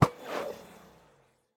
endereye_launch2.ogg